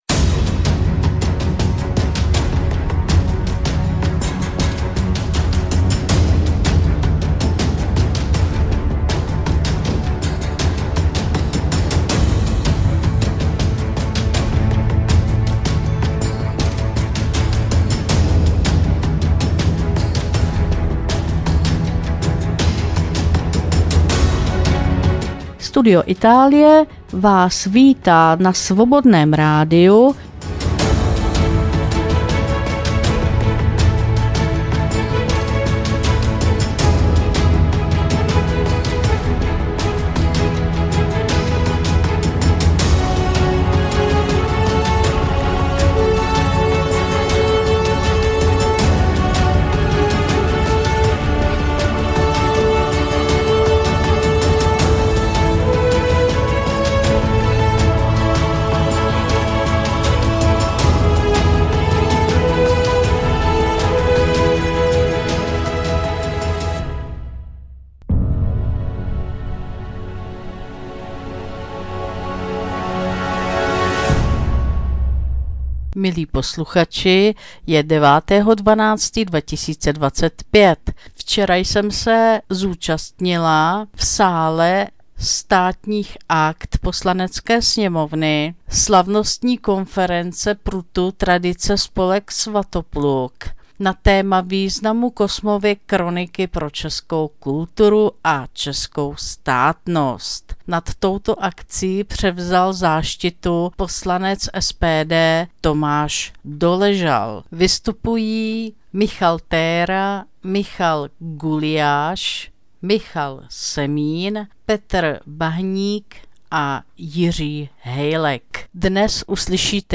Konference prutu Tradice Spolek Svatopluk se konala pod záštitou poslance SPD Tomáše Doležala a to v sále Státních akt Poslanecké sněmovny 08.12.2025. Vystoupili